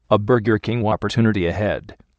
burger_king_whopper_alert.mp3